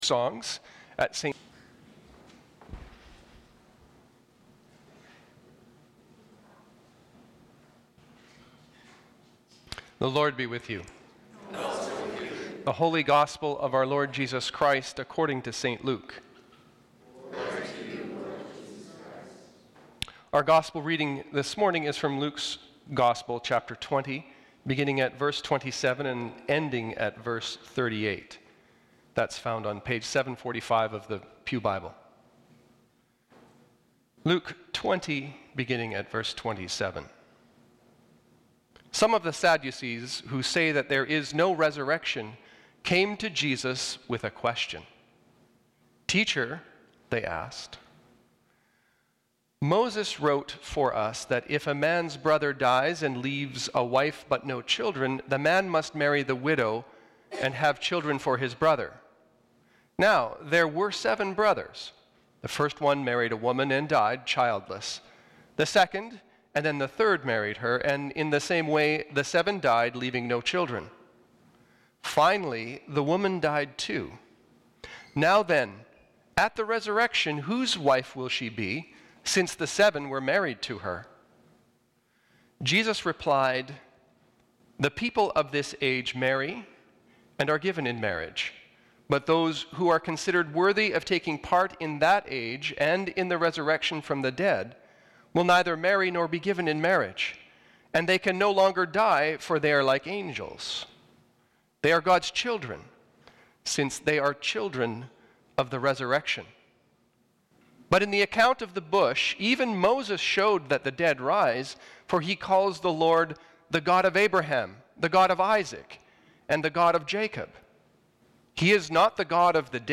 Sermons | St. Paul's Anglican Church